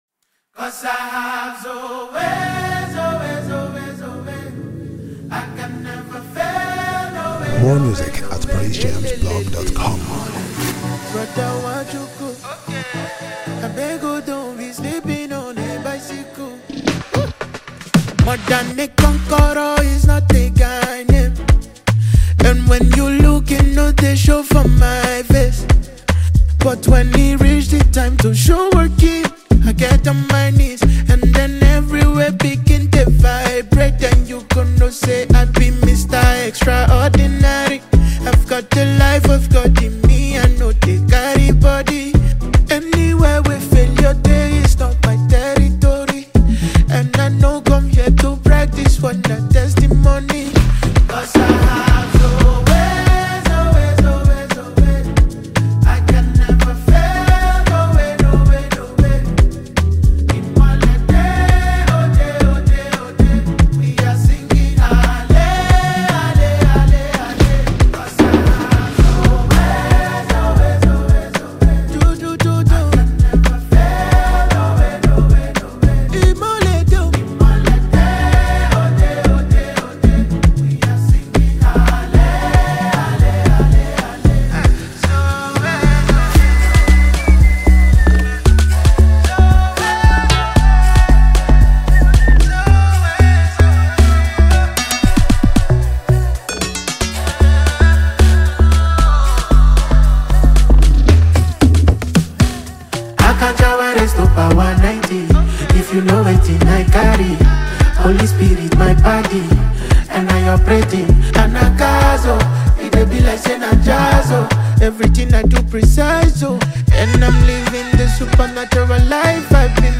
2024-11-05 Afrogospel / Amapiano, New Music Leave a comment
With vibrant beats and an infectious rhythm